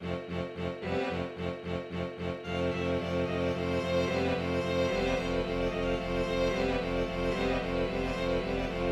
Le Divertimento Sz 113 (BB 118) pour orchestre à cordes est une œuvre de Béla Bartók écrite peu avant son exil aux États-Unis.
Le premier mouvement, entre les tutti et le concertino, laisse peu à peu percer des forte aigus de la part des cordes, suivis de pianissimos graves, qui dramatisent ce qui se présentait comme un simple "divertissement".